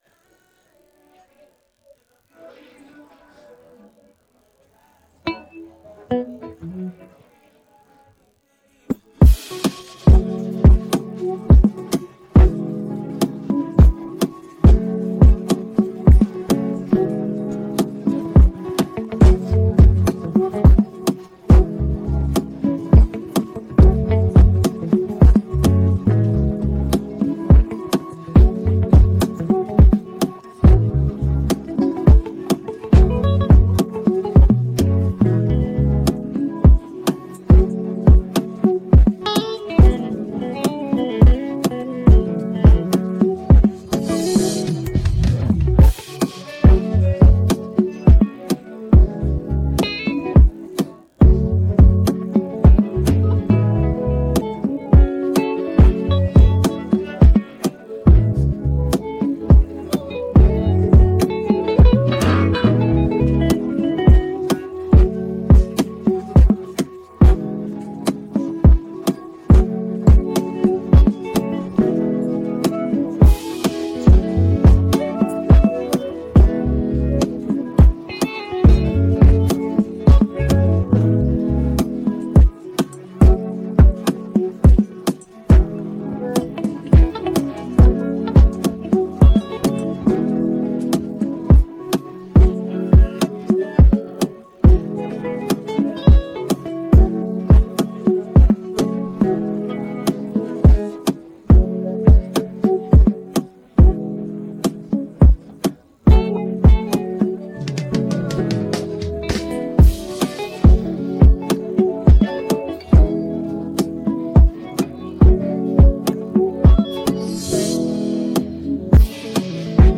Instrumentals
Here is the official Instrumental